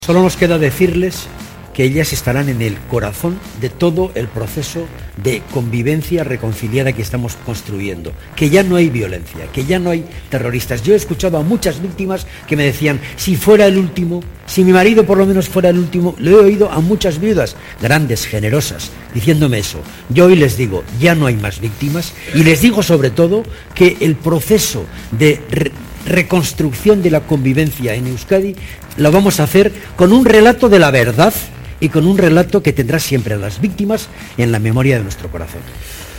Fragmento de la entrevista de Ramón Jáuregui en Al Rojo Vivo, de La Sexta, en el que rinde homenaje a las víctimas de ETA el día en el que el Tribunal de Derechos Humanos de Estrasburgo condena a España por la doctrina Parot 21/10/2013